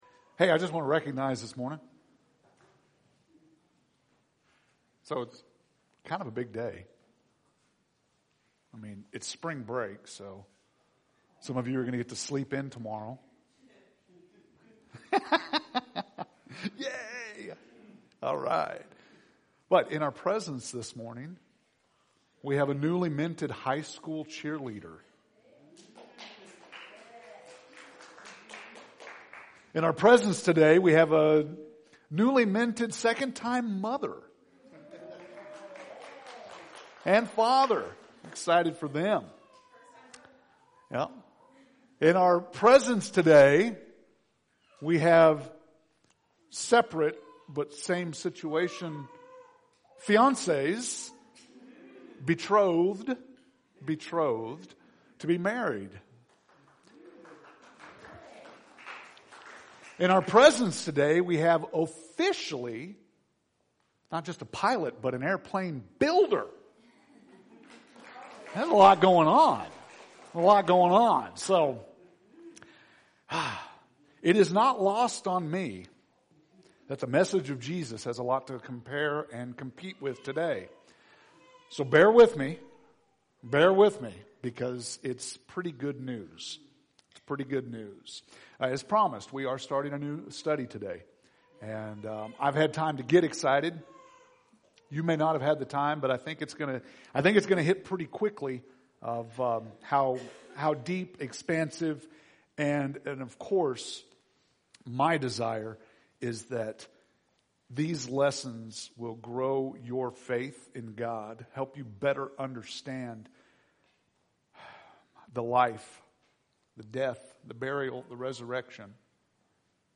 March 15th – Sermons